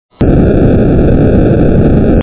classical_light.mp3